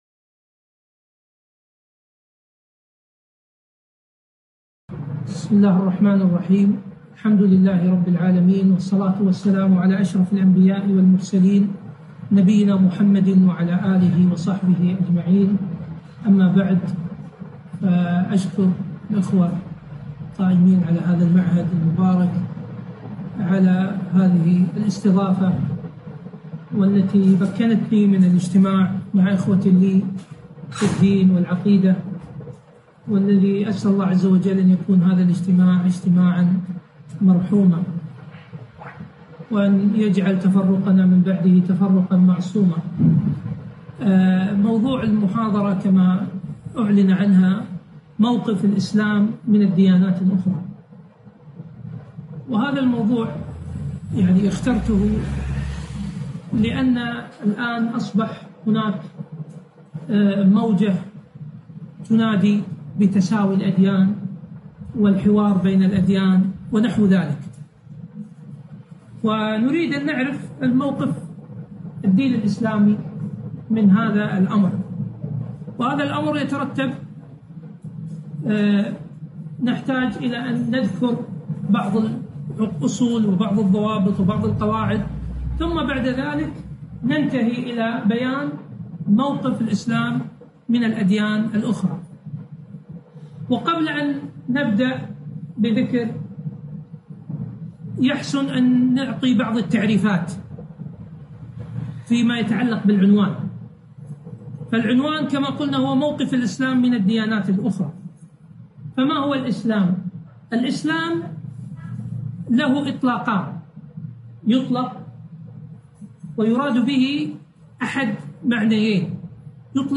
محاضرة - موقفُ الإسلامِ من الديانات الأخرى